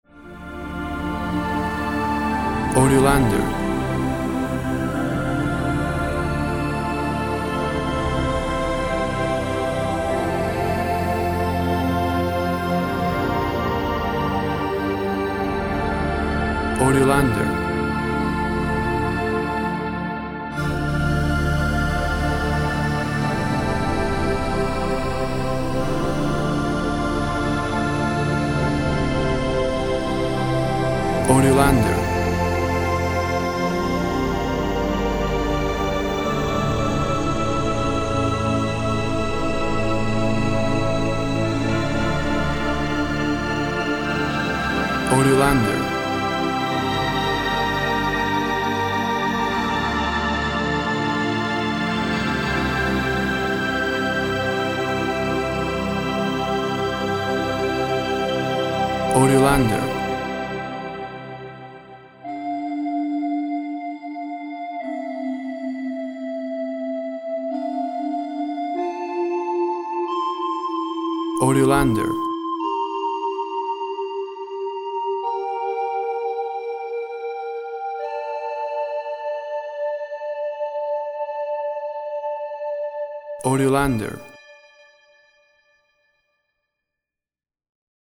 Thick synth sounds rising then falling to faintness.
Tempo (BPM) 44